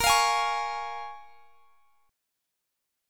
Bbm7 Chord
Listen to Bbm7 strummed